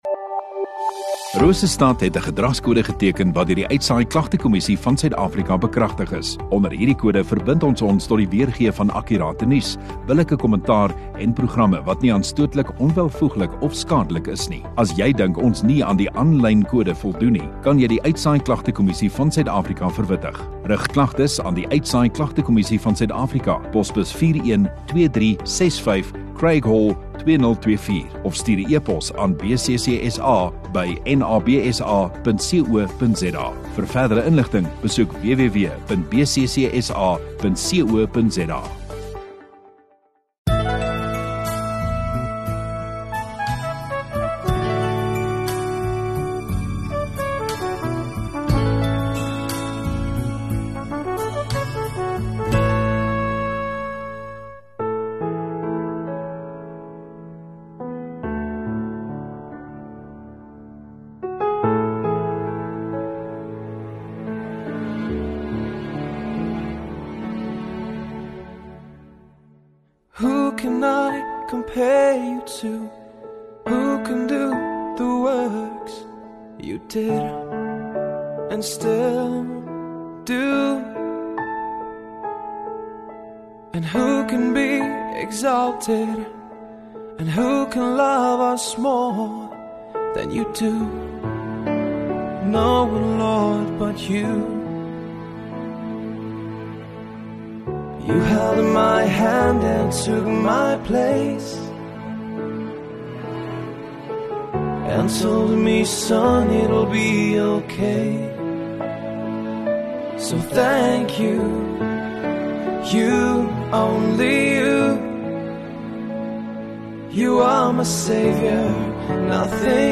2 Jun Sondagoggend Erediens